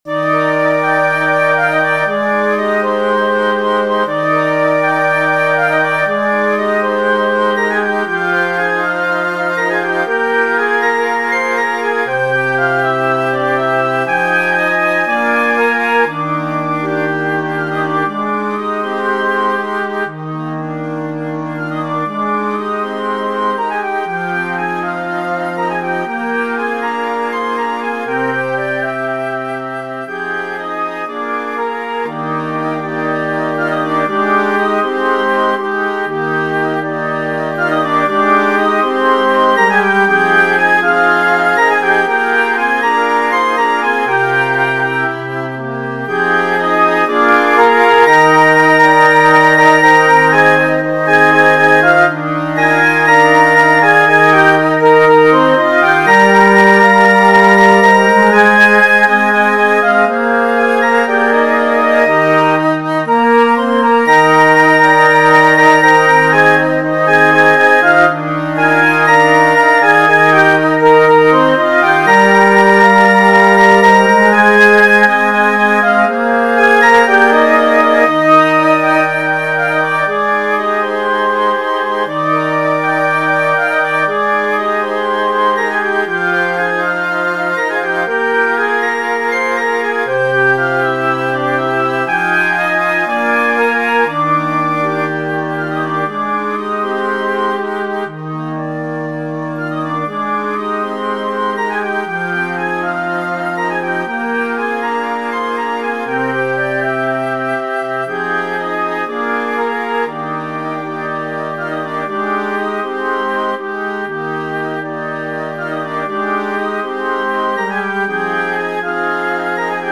This arrangement is for flute choir.